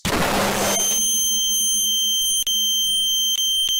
largeblast1.ogg